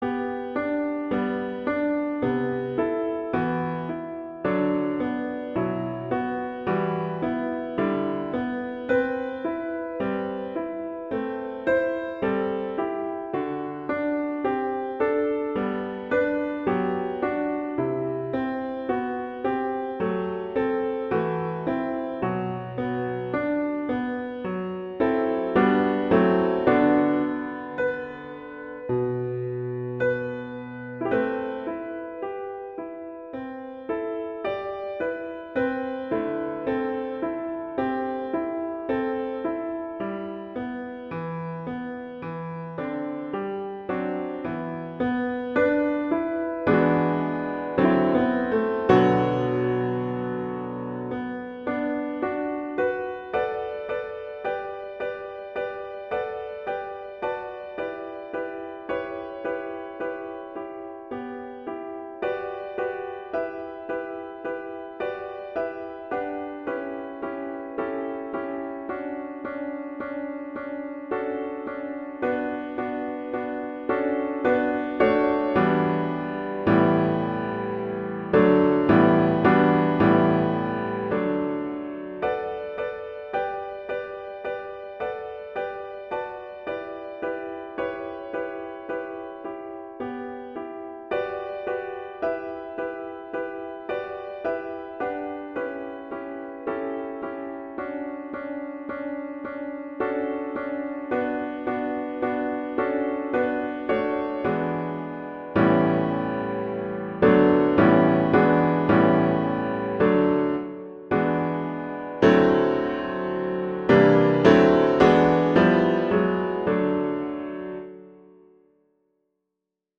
arrangement for piano solo
in G major, simplified version.
classical, french
G major
♩=54 BPM